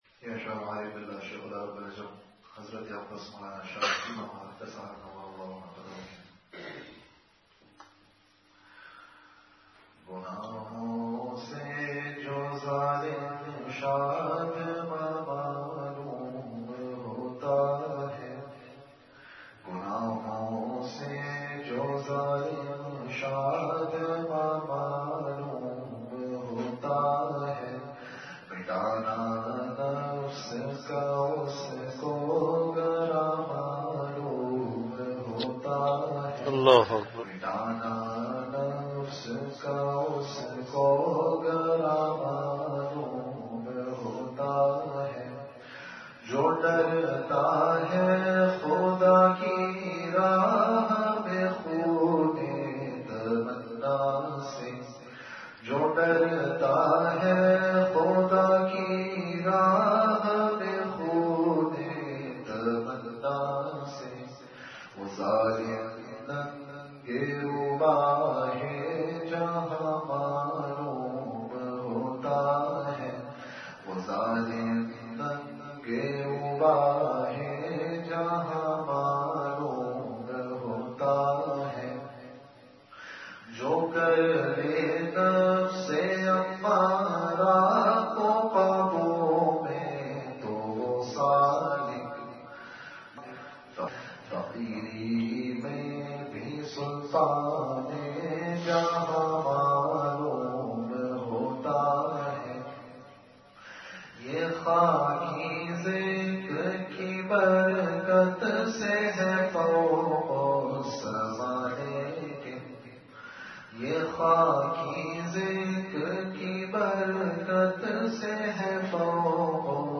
Majlis-e-Zikr
Event / Time After Isha Prayer